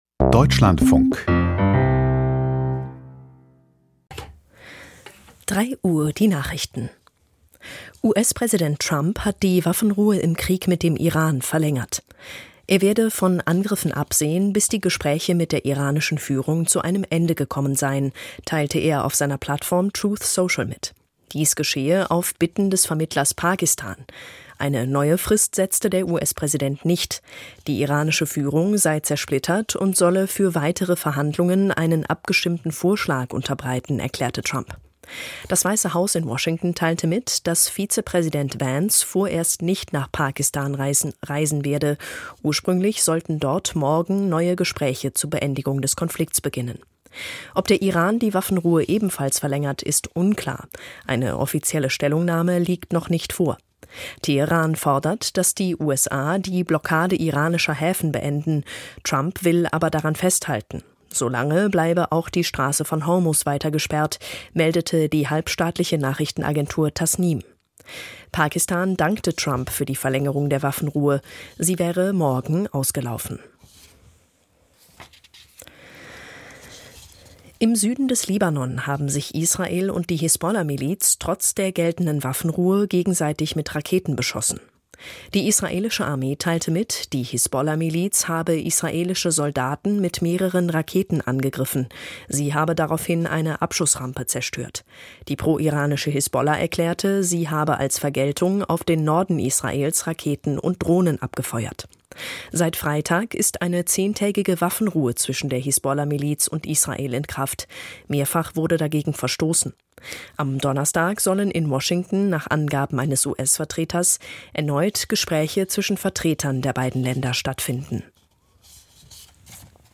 Die Nachrichten vom 22.04.2026, 03:00 Uhr